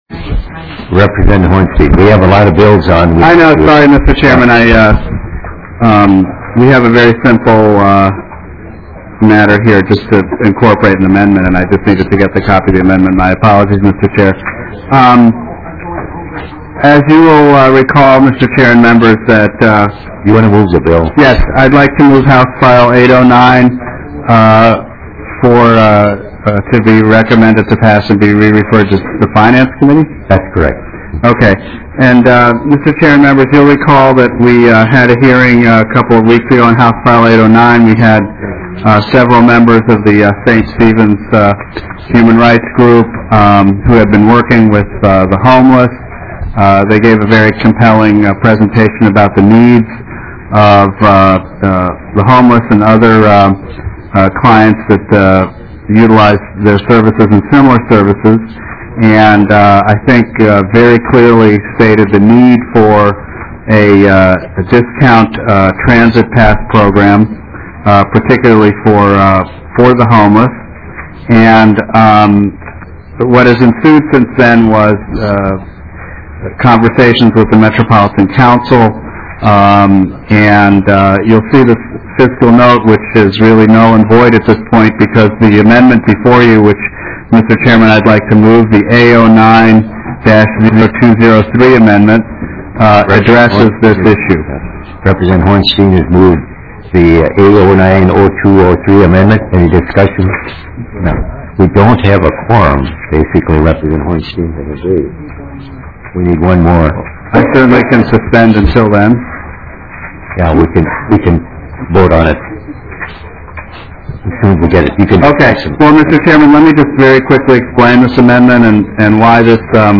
Transportation Finance and Policy Division SEVENTEENTH MEETING - Minnesota House of Representatives
05:19 - Gavel.